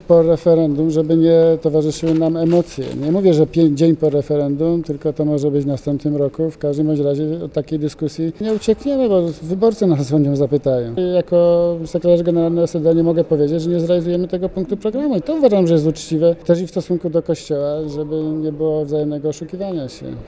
Słuchaj co powiedział Marek Dyduch